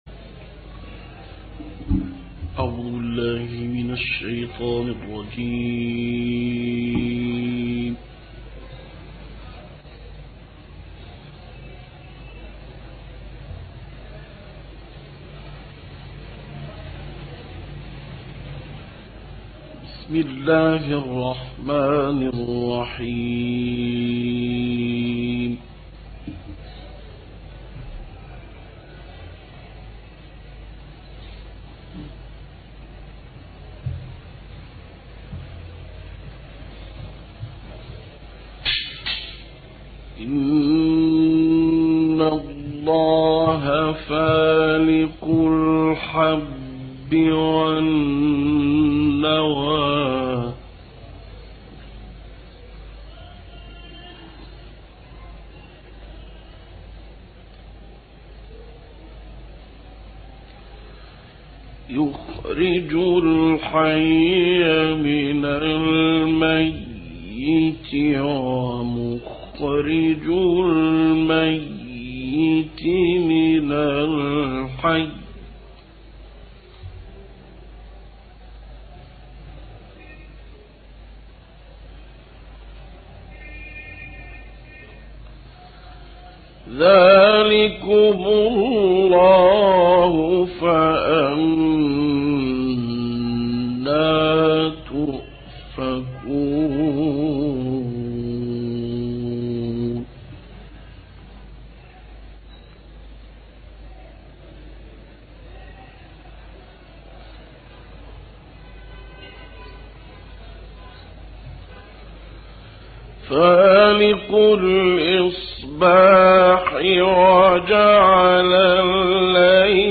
گروه شبکه اجتماعی: به مناسبت سی و ششمین سالگرد درگذشت محمود محمد رمضان تلاوت‌های کمتر شنیده شده‌ای از این قاری برجسته و دو تلاوت از قاریان به‌نام مصری در مراسم وفات این قاری، ارائه می‌شود.